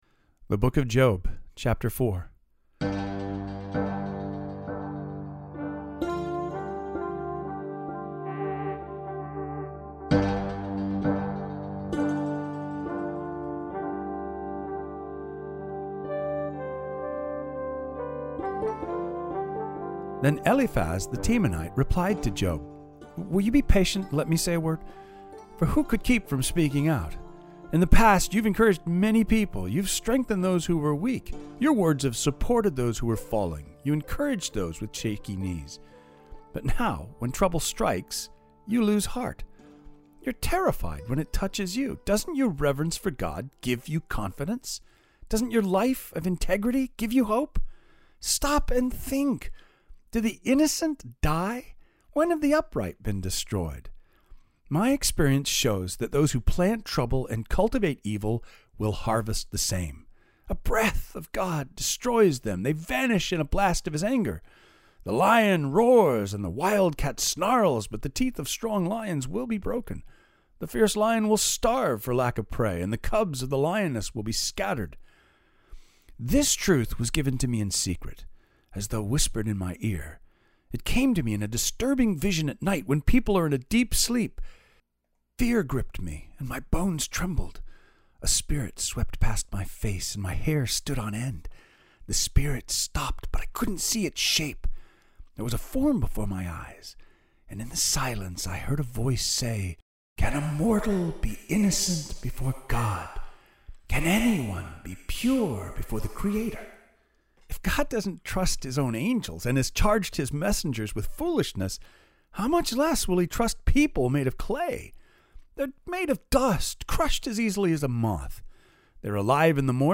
We’ll be covering a lot of ground, ch 4-7 – which I will only read an abridged version of on Sunday morning, so listen to the audio reading of those chapters in their entirety above.